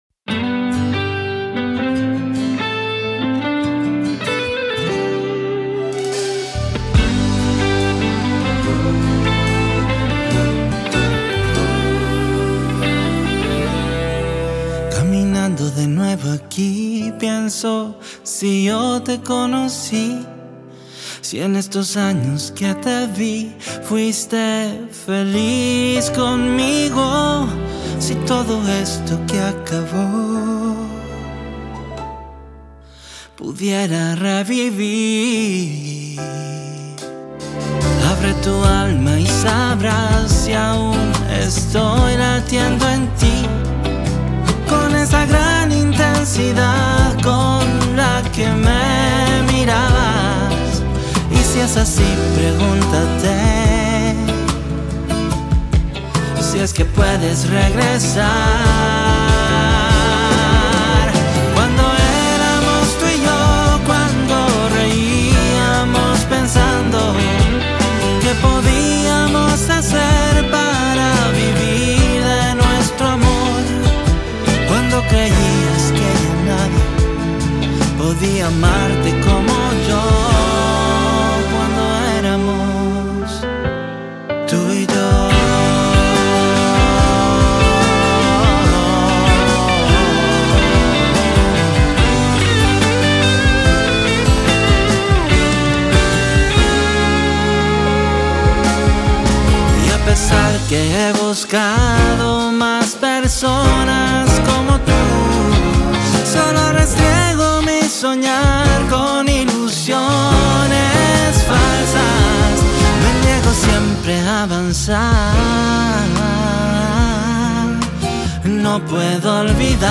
guitarras